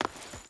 Footsteps
Stone Chain Run 3.wav